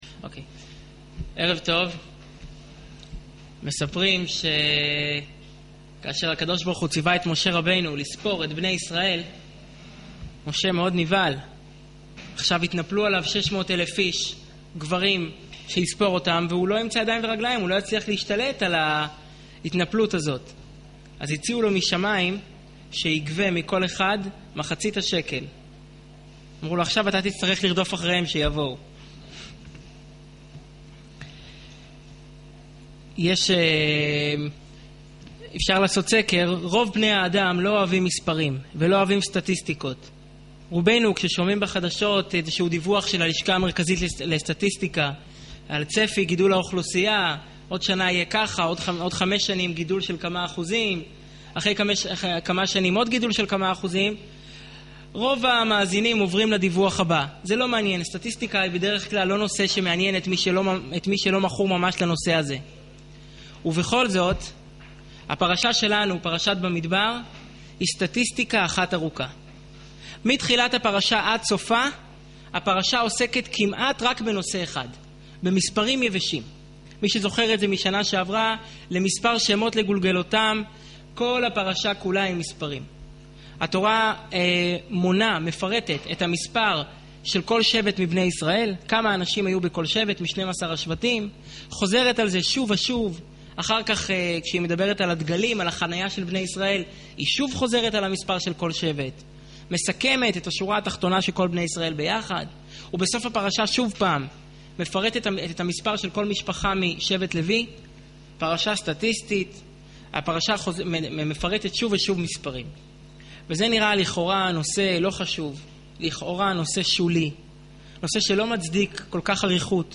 שיעור מרתק לפרשת במדבר